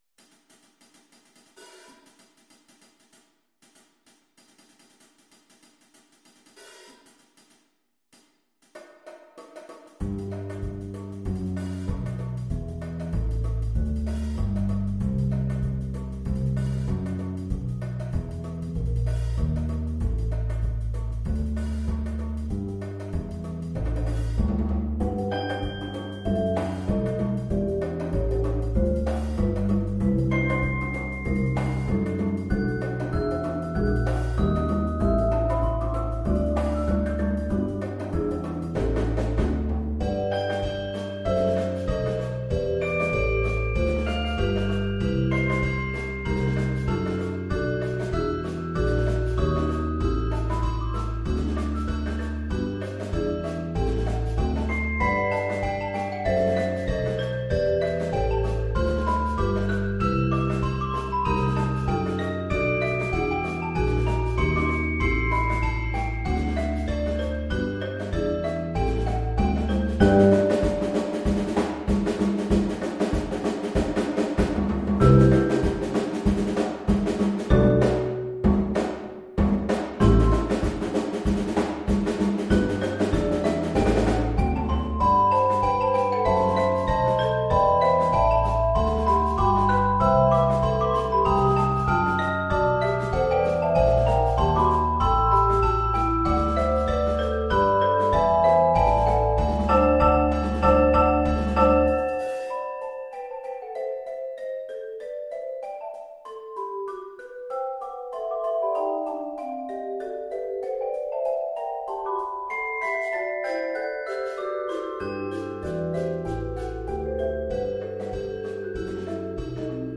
Genre: Percussion Ensemble
# of Players: 8+
Bells
Xylophone
Vibraphone
Bass Guitar (optional)
Bongos
Congas & Cabasa
Drum Set